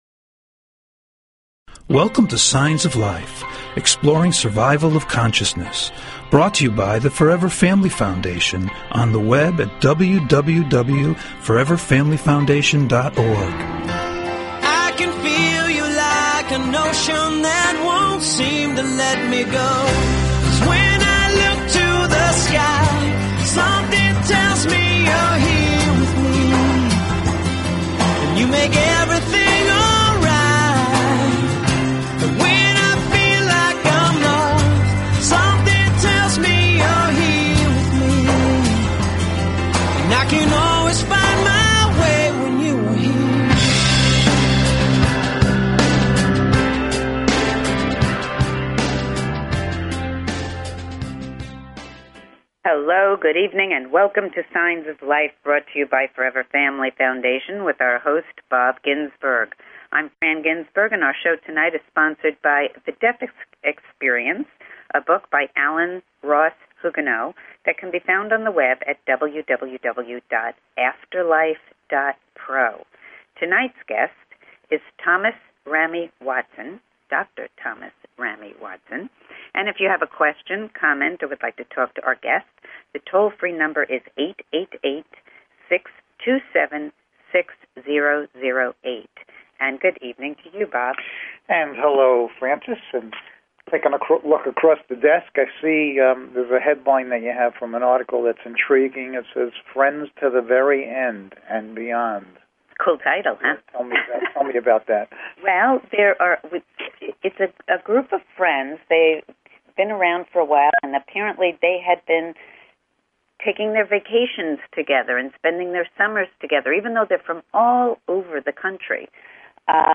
Talk Show Episode, Audio Podcast, Signs_of_Life and Courtesy of BBS Radio on , show guests , about , categorized as
Call In or just listen to top Scientists, Mediums, and Researchers discuss their personal work in the field and answer your most perplexing questions.